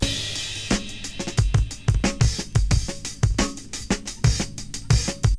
I kept myself to a few drum sounds, primary this loop, which comes from a Jimmy Smith tune originally: